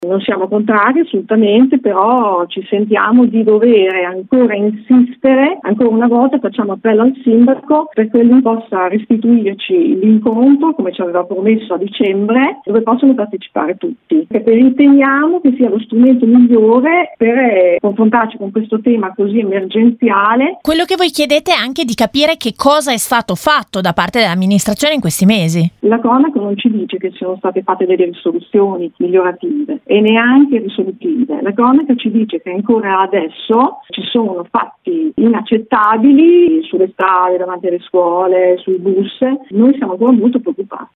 mamma del comitato Noi Reagiamo, intervistata